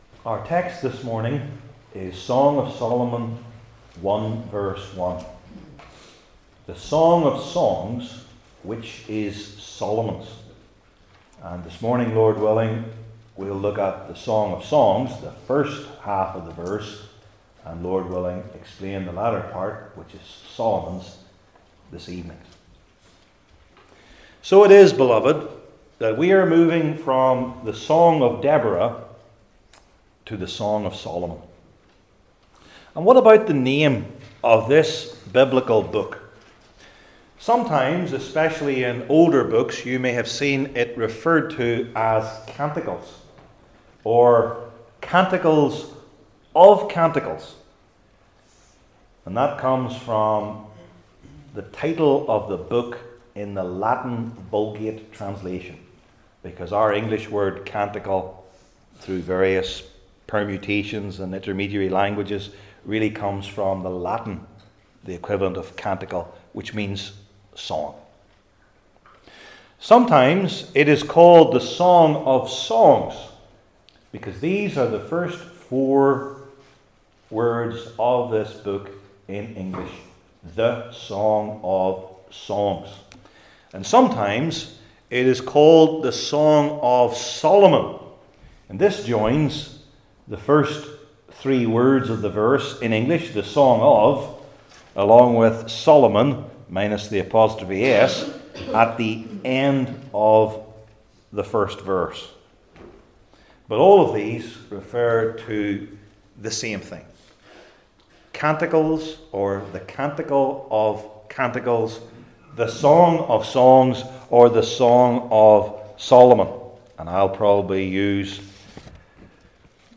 Old Testament Sermon Series I. The Meaning II.